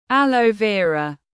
Aloe vera /ˌæl.əʊ ˈvɪə.rə/